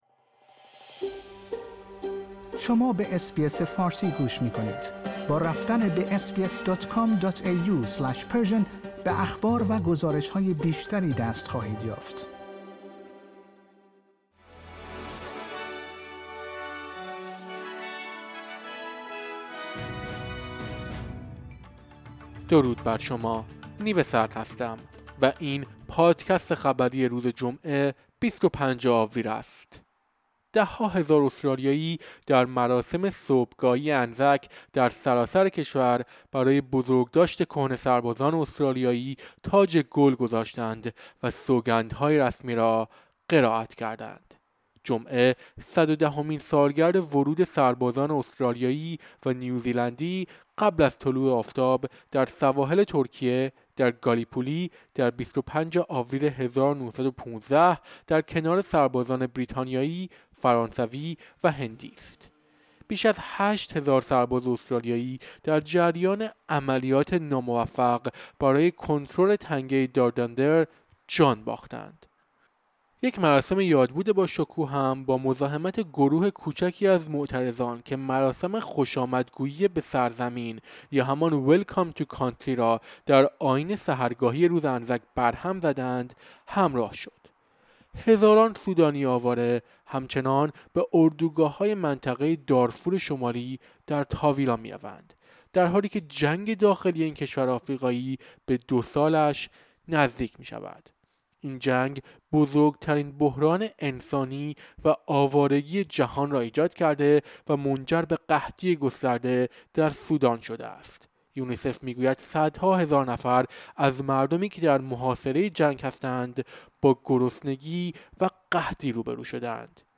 در این پادکست خبری مهمترین اخبار امروز جمعه ۲۵ آپریل ارائه شده است.